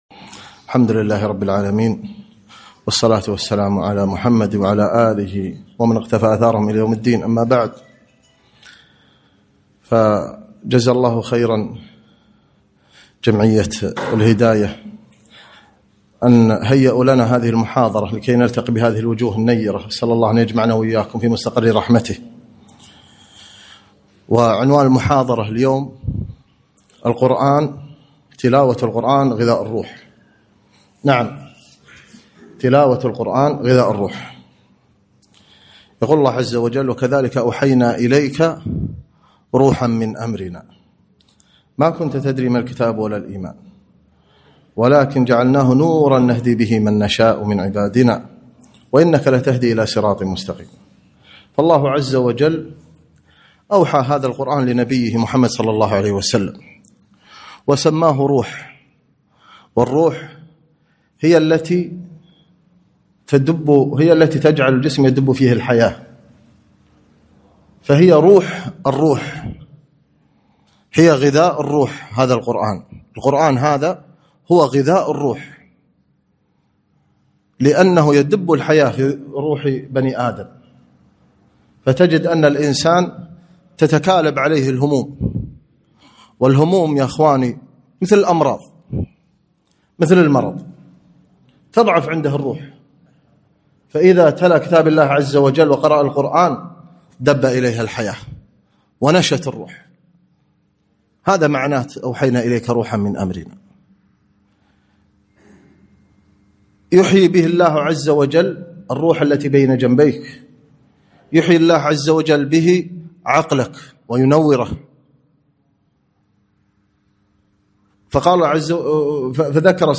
كلمة - تلاوة القرآن غذاء الروح